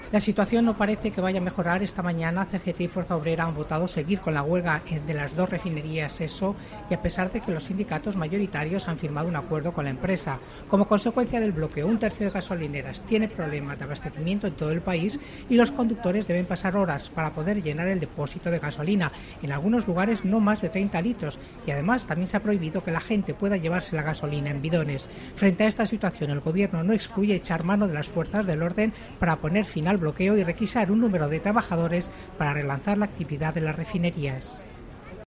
Los conductores pasan horas en las gasolineras para llenar no más de 30 litros. Crónica